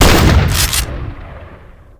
Index of /server/sound/weapons/supershotty
ssgfire.wav